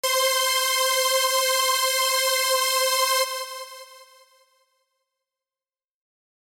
Because we are going to make it sound even bigger!
• Feedback Volume 45%